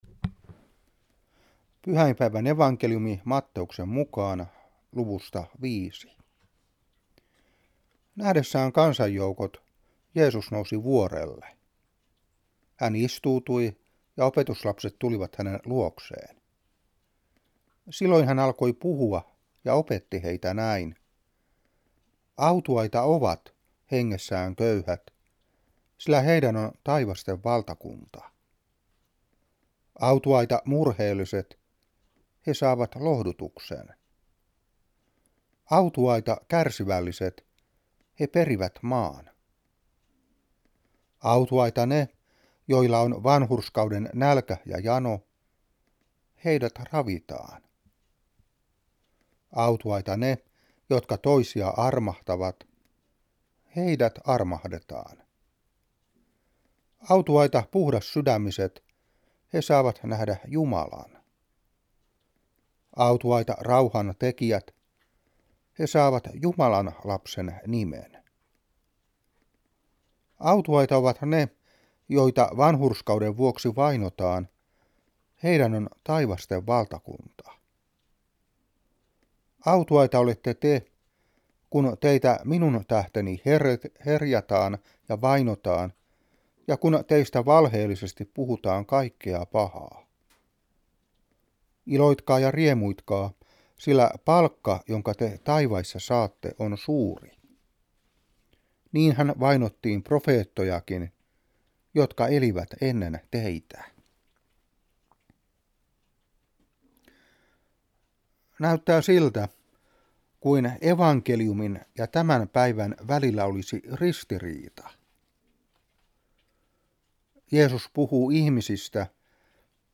Saarna 1993-11.